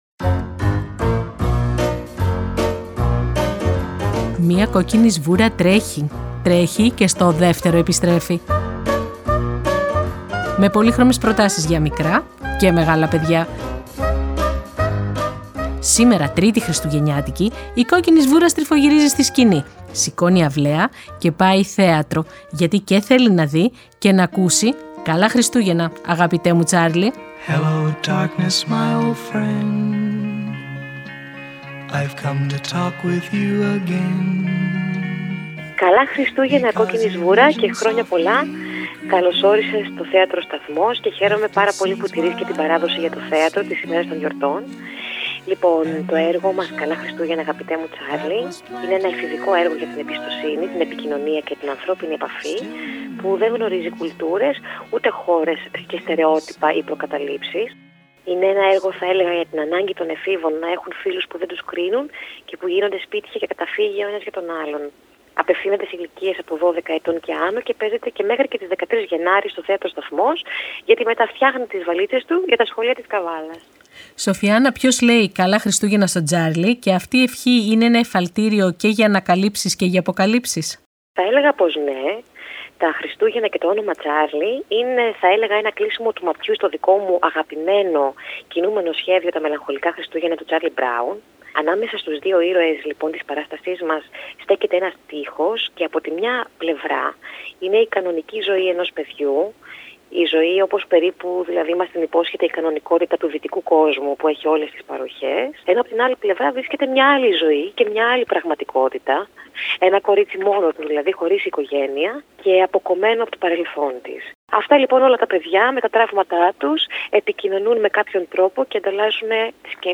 Επιμέλεια – Παρουσίαση